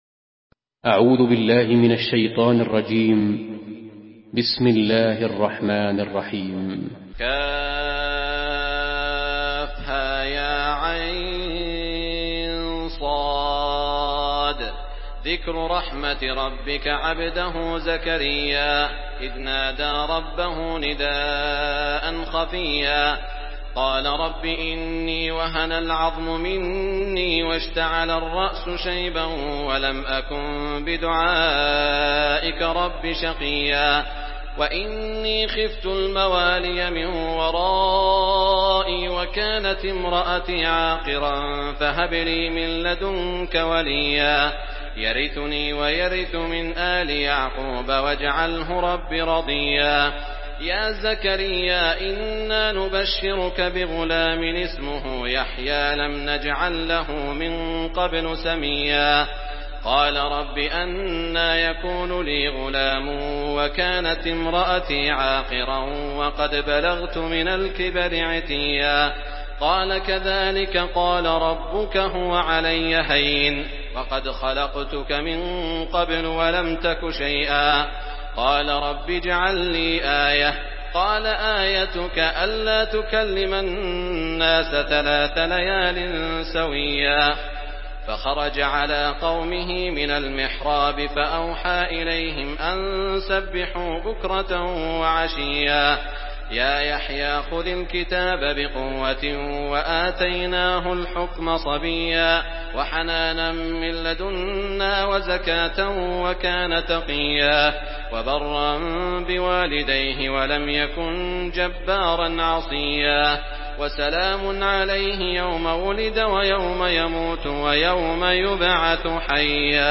Surah Maryam MP3 in the Voice of Saud Al Shuraim in Hafs Narration
Murattal Hafs An Asim